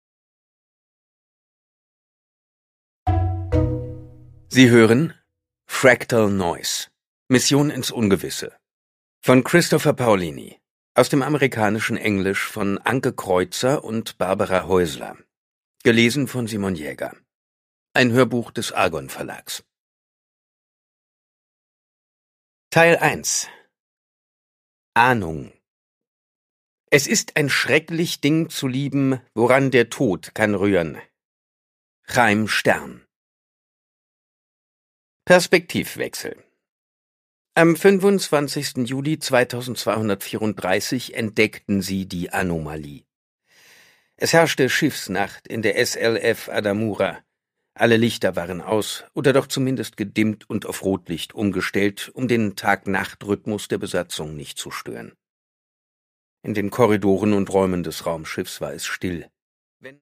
Produkttyp: Hörbuch-Download
Gelesen von: Simon Jäger
Simon Jäger macht mit seiner markanten, eindringlichen Stimme diese Space Opera zu einem spannenden Hörerlebnis.